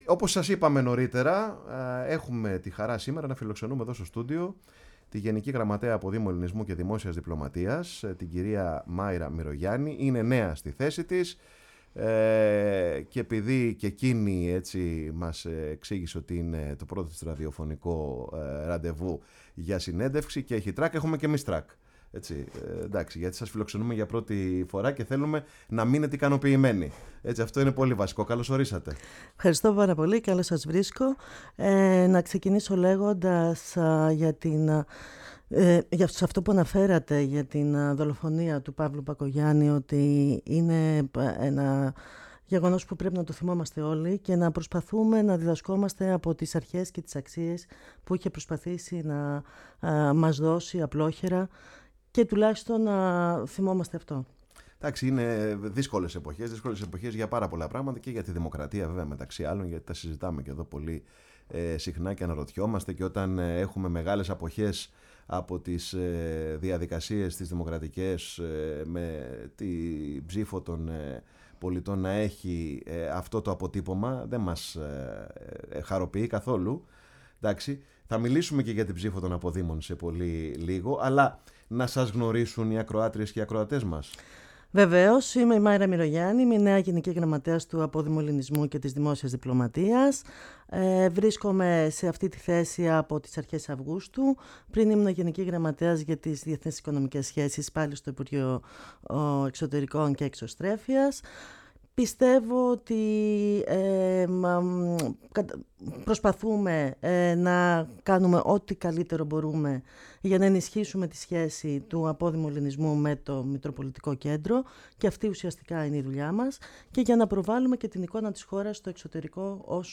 φιλοξενήθηκε σήμερα στο στούντιο της Φωνής της Ελλάδας